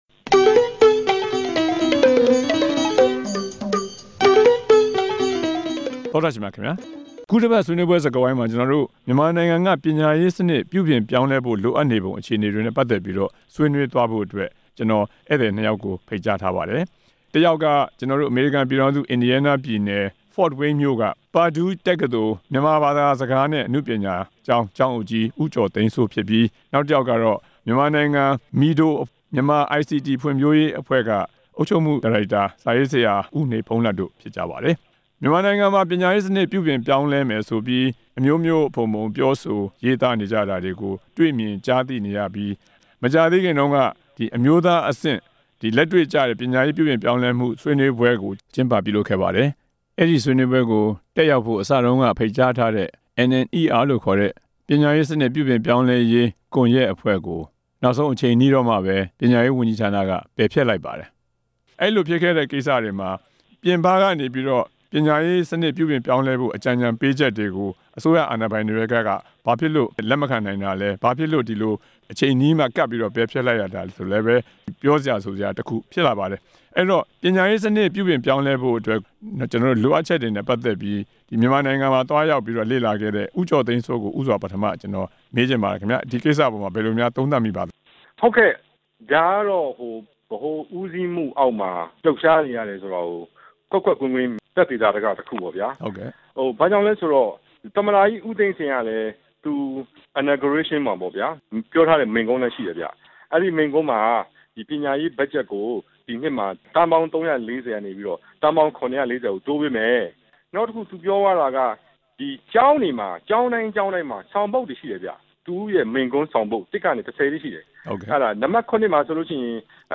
ပညာရေးမူဝါဒ ပြုပြင်ပြောင်းလဲရေး ကြိုးပမ်းမှု ဆွေးနွေးချက်